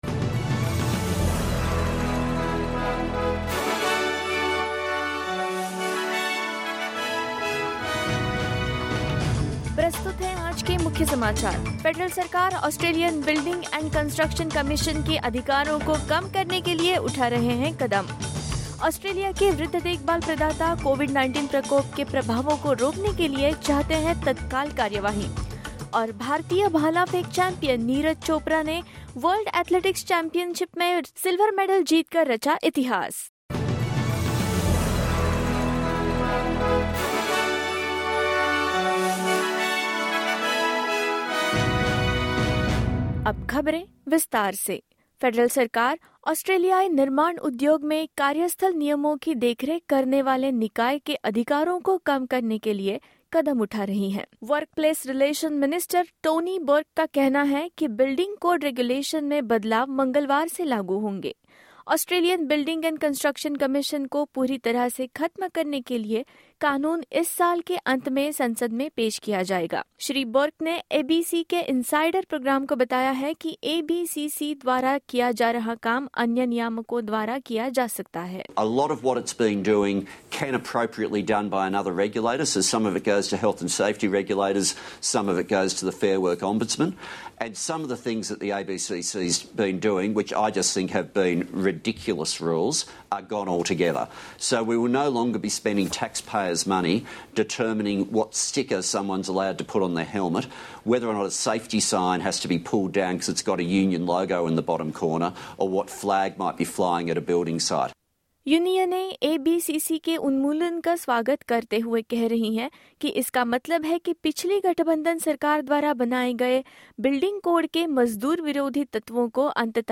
In this latest SBS Hindi bulletin: Aged care providers worried about recent COVID-19 outbreak as more than 6,000 residents were infected last week; Australia's building code regulation changes will be effective from Tuesday; Indian athleteNeeraj Chopra wins a historic silver medal at the World Athletics Championships and more.
hindi_news_2407_new.mp3